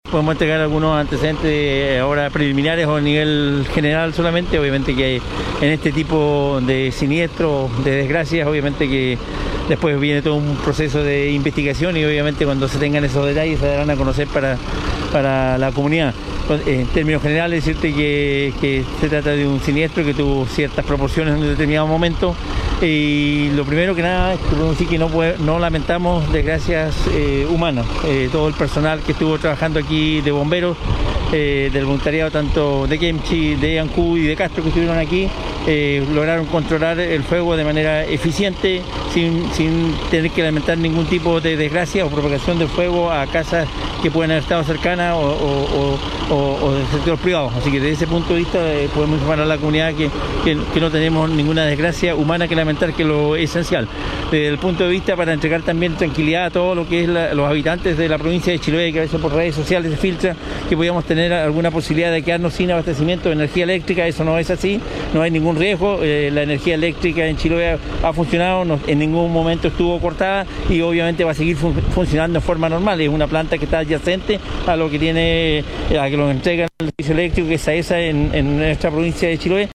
En tanto, el Gobernador Provincial de Chiloé, Pedro Andrade Pérez, quien acudió al lugar para interiorizarse en terreno de la emergencia, indicó: